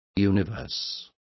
Complete with pronunciation of the translation of universes.